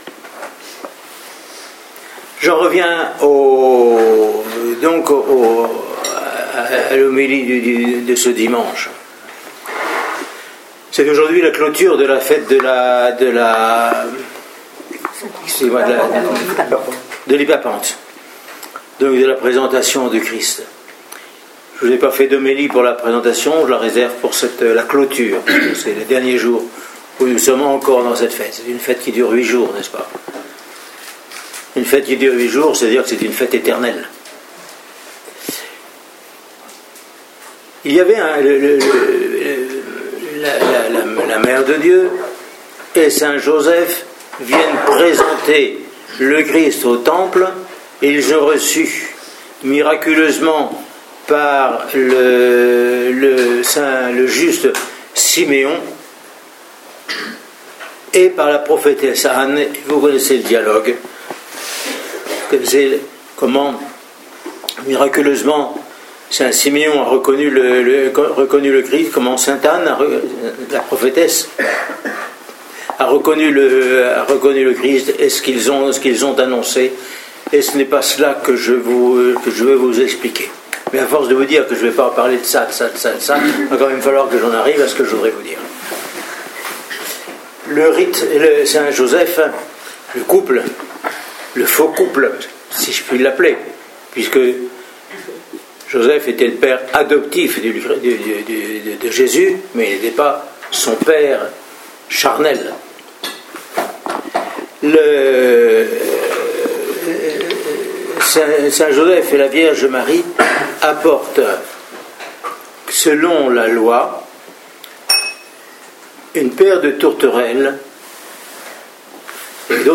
au monastère de la Transfiguration lors du dimanche de la cloture de la fête de l’Hypapante